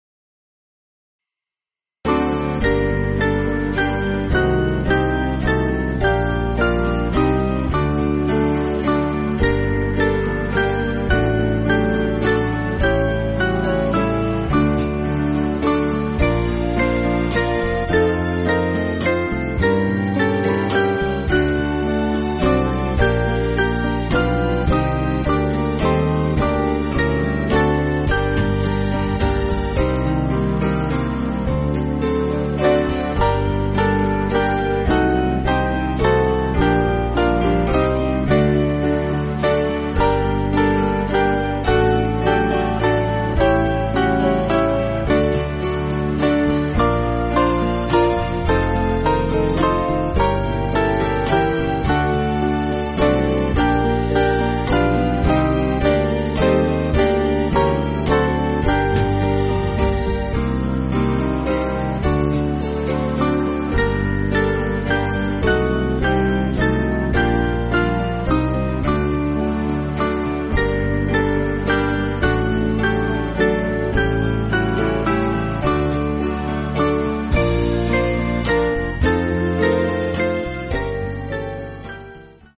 Small Band
(CM)   4/Gm